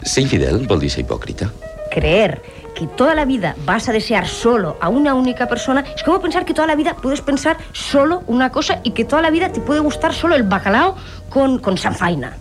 Opinió d'una entrevistada sobre la fidelitat a la parella.
Divulgació
FM